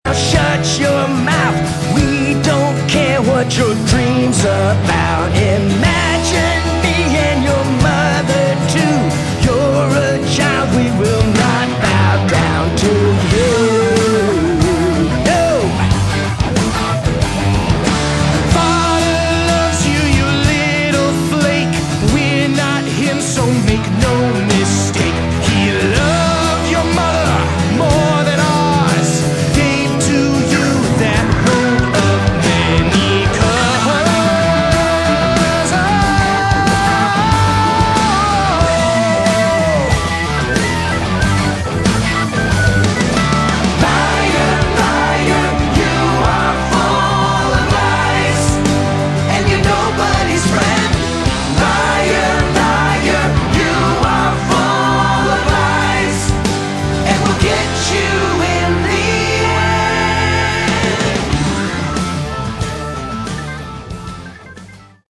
Category: Prog Rock
guitars